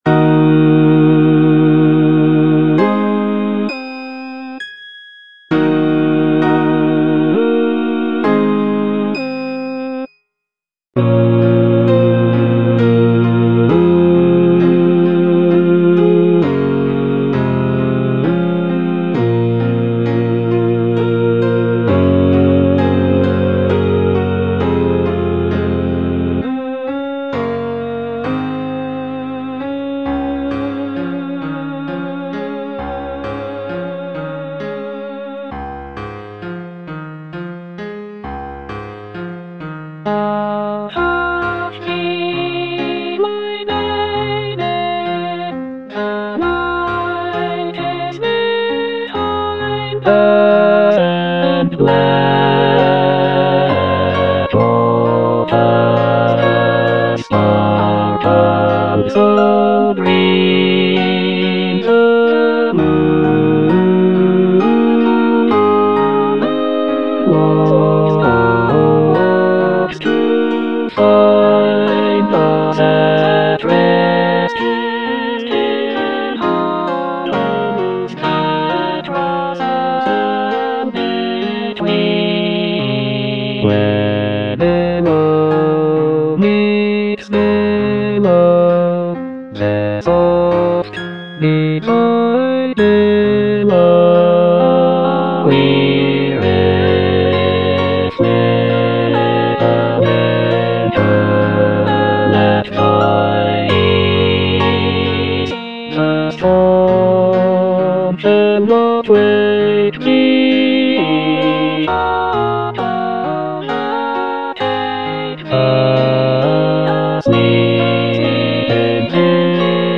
Bass II (Emphasised voice and other voices)